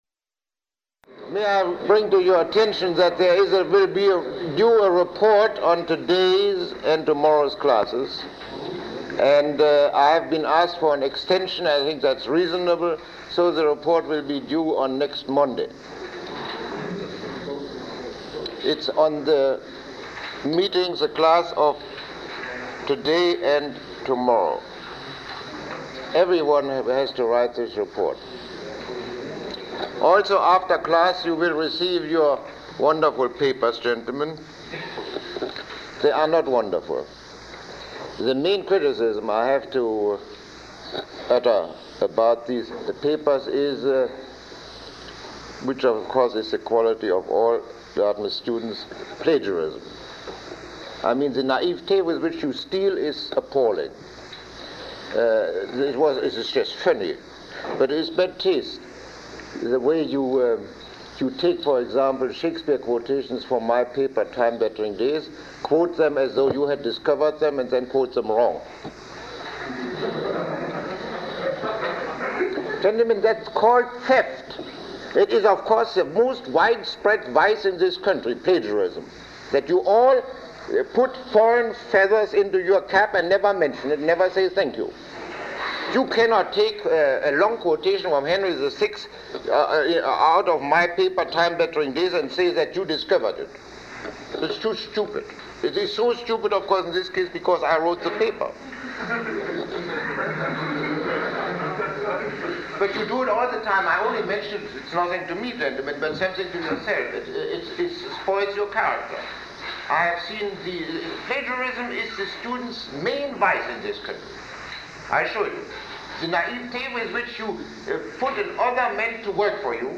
Lecture 22